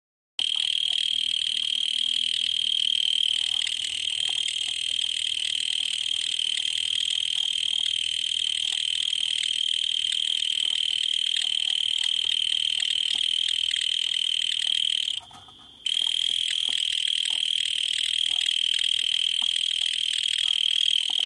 De diertjes zitten in een holletje in de grond, en met hun achterlijf maken ze een langdurig geluid, soms wel minutenlang. Voor de grap hebben we de geluidssterkte gemeten: op zo’n halve meter is dat 85 dB.
In het veld moeten er honderden zitten want zodra de schemering ingaat maken ze een enorme herrie.